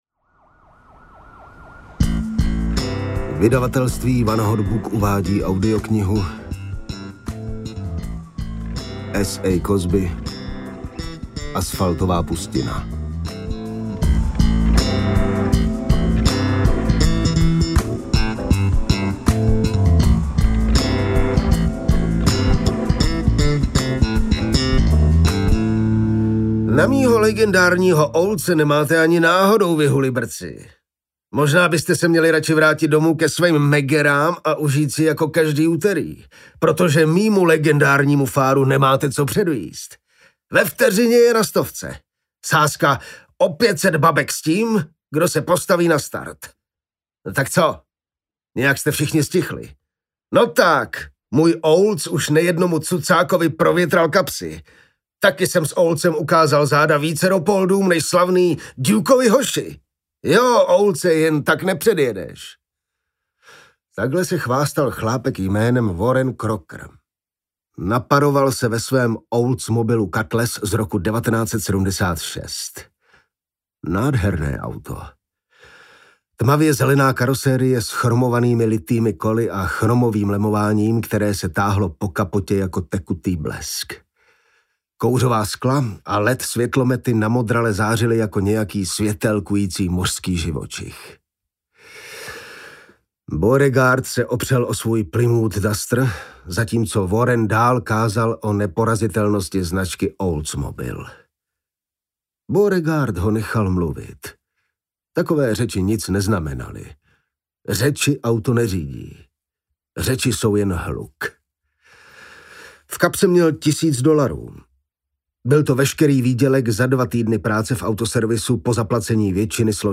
Asfaltová pustina audiokniha
Ukázka z knihy
• InterpretJan Teplý ml.